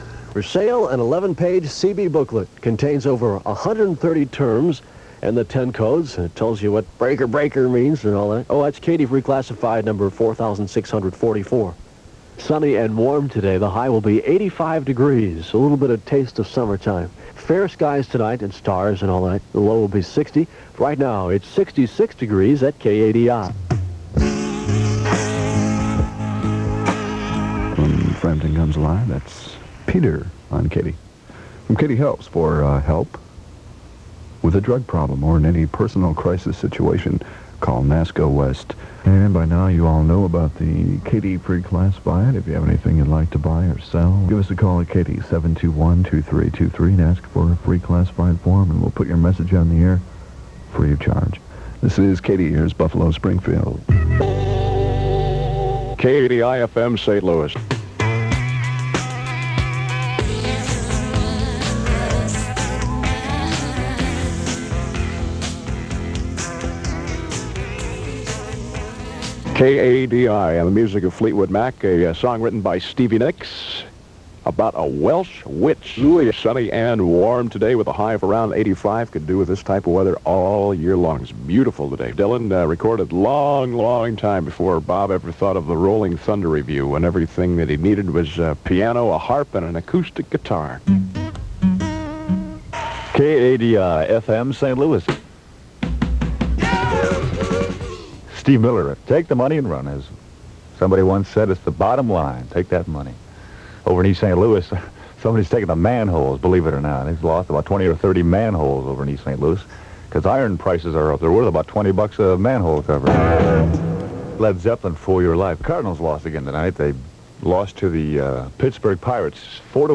KADI-FM Various Announcers aircheck · St. Louis Media History Archive
Original Format aircheck